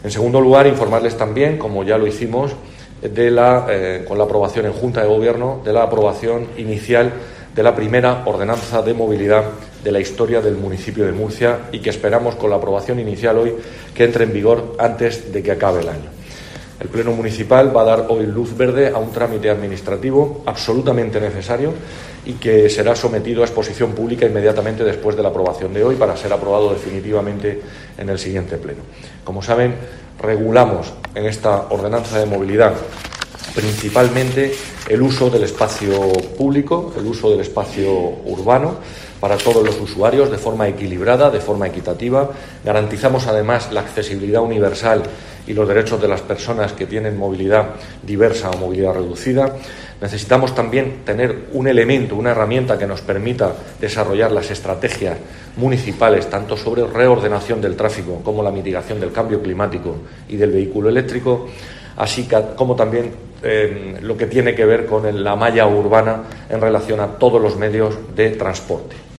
José Francisco Muñoz, concejal de Movilidad